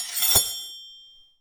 SWORD_06.wav